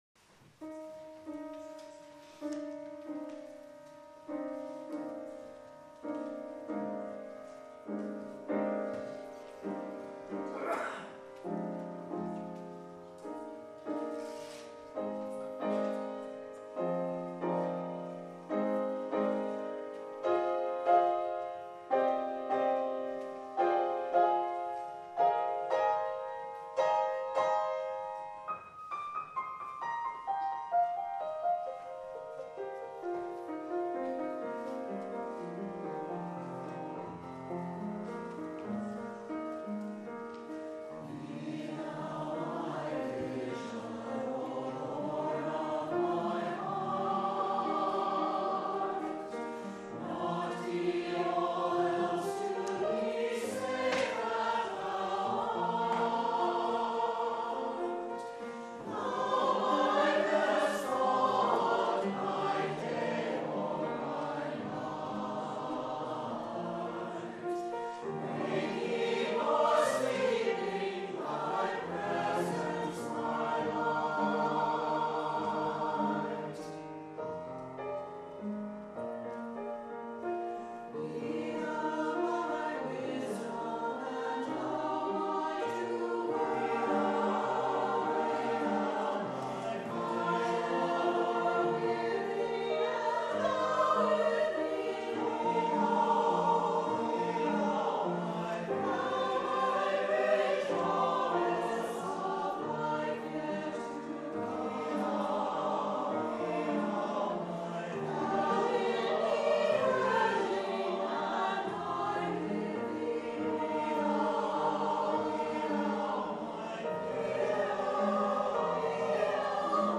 for SATB Chorus, Opt. Children's Chorus, and Piano (2006)